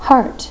heart.wav